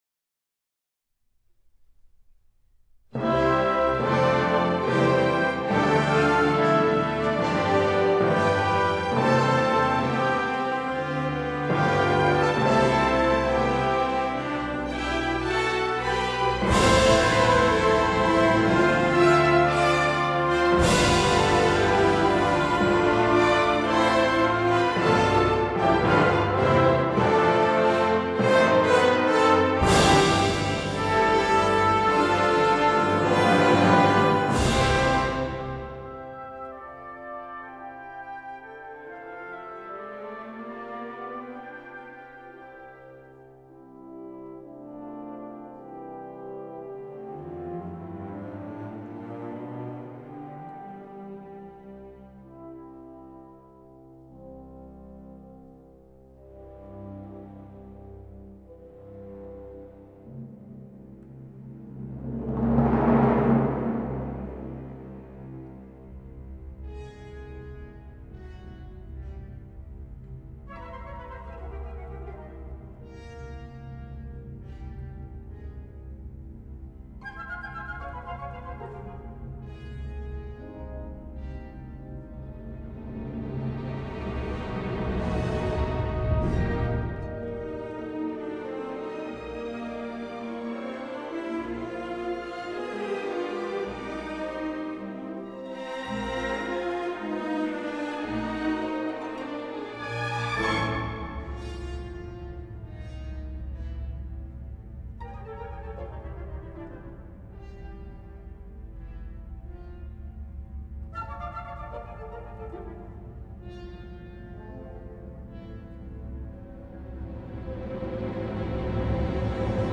موسیقی کلاسیک: Anton Arensky - Dream on the Volga, opera, Op. 16 Overture
ارکسترال
01._dream_on_the_volga_opera_op._16_overture.mp3